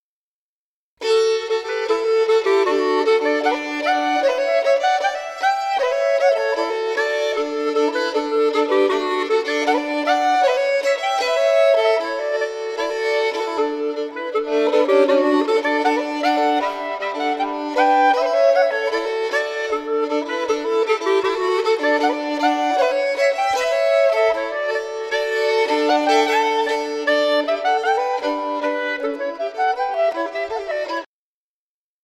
Schottis
Inspelad: Harplinge kyrka